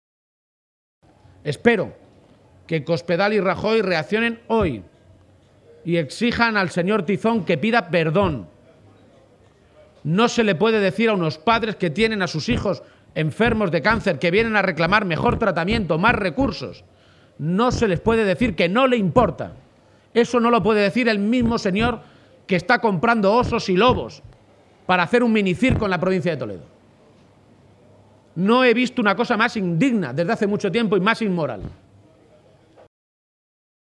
García-Page se pronunciaba de esta manera esta mañana en una comparecencia ante los medios de comunicación, durante su visita a las Ferias de Mayo de Talavera.
Cortes de audio de la rueda de prensa